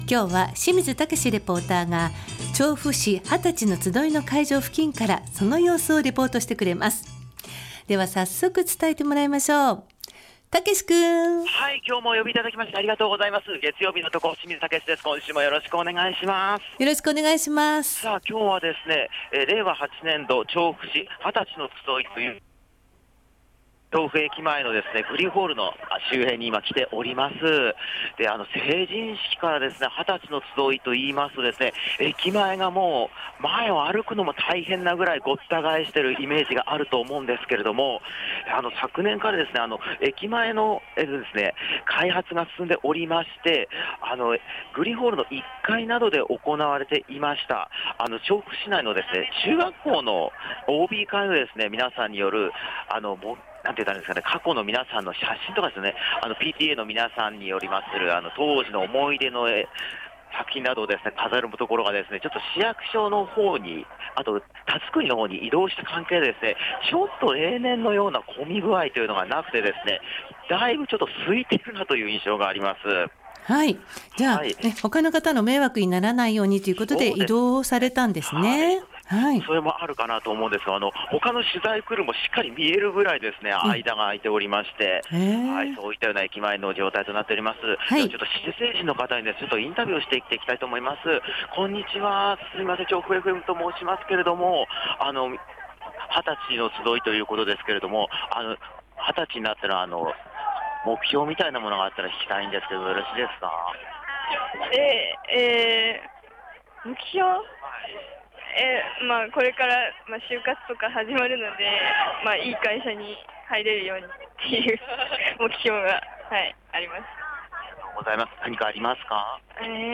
成人の日、今年も雲ひとつない青空の下からお届けした本日の街角レポートは 旧・成人式こと「令和8年調布市二十歳のつどい」のレポートです。